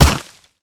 snd_snowball.ogg